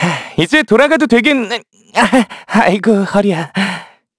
Evan-Vox_Victory2_kr.wav